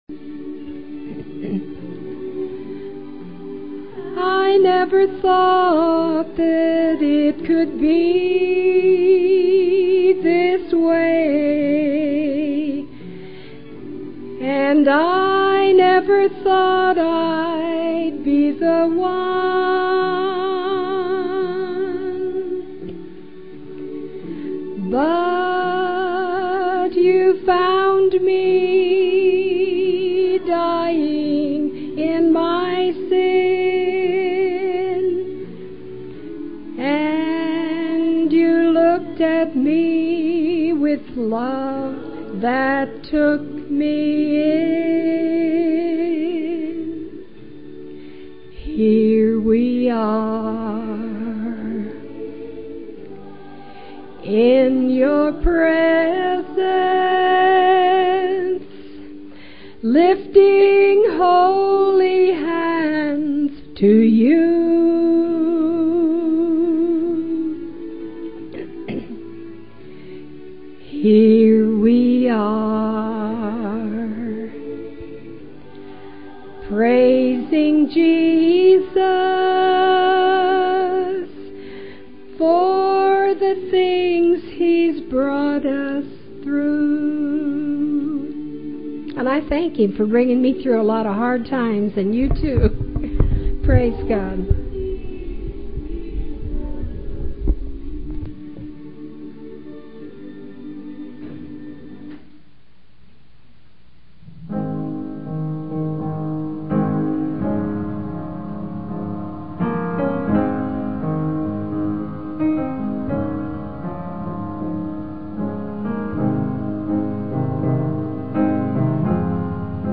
PLAY Fifth Sunday Night Sing, Oct 29, 2006